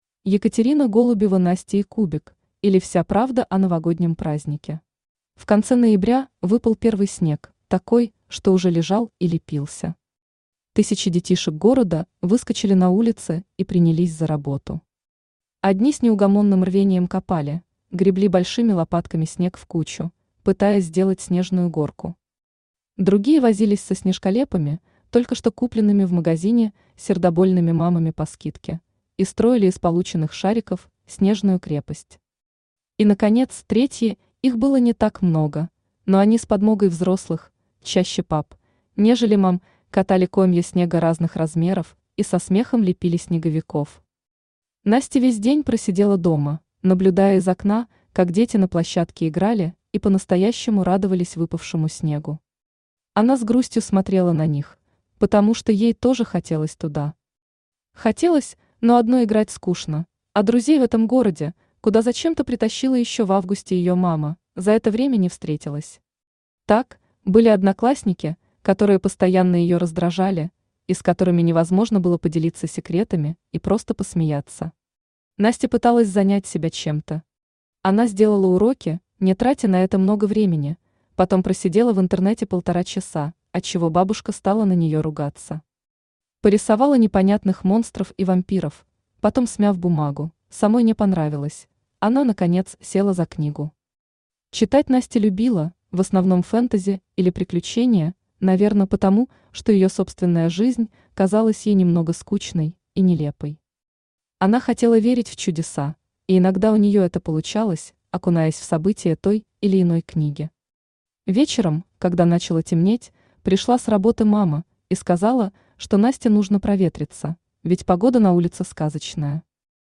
Аудиокнига Настя и Кубик, или Вся правда о новогоднем празднике | Библиотека аудиокниг